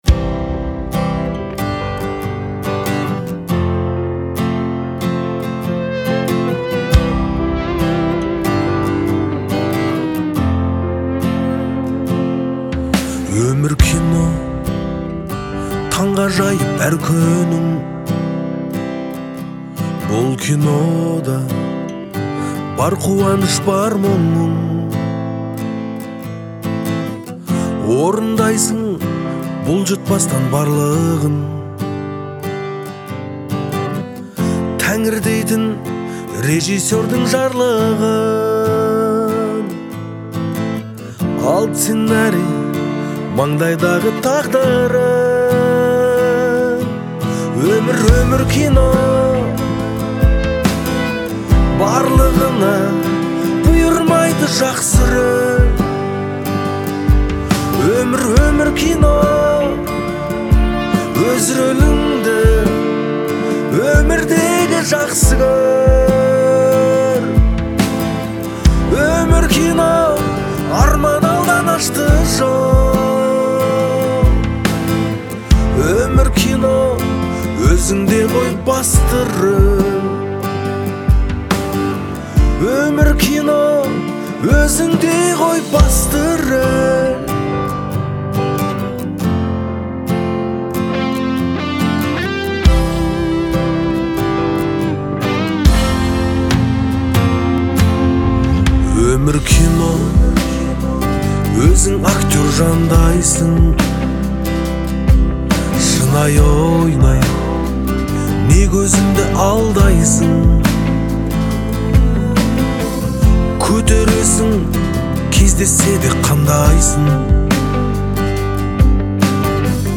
это глубокая и эмоциональная песня в жанре поп-рок